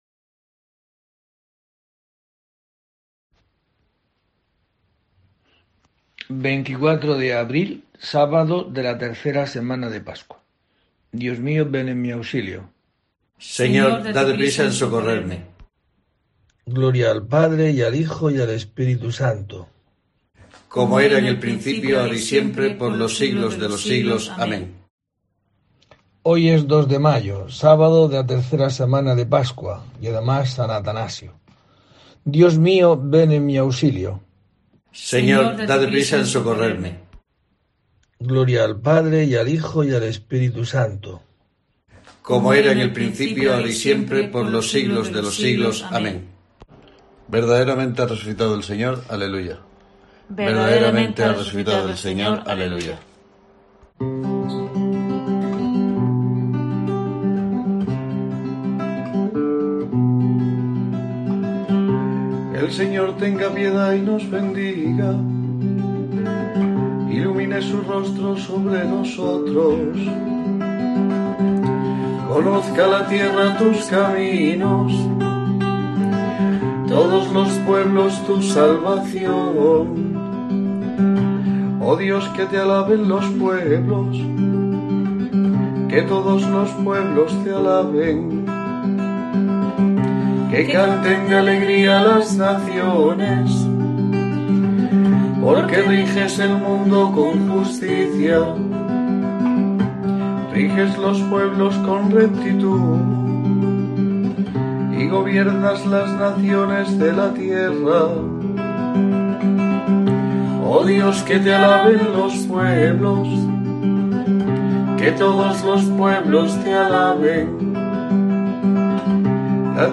24 de abril: COPE te trae el rezo diario de los Laudes para acompañarte